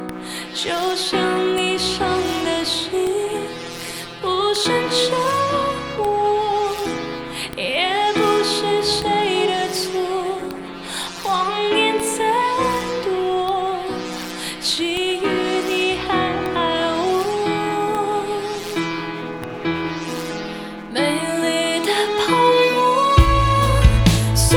female_wm_release.mp3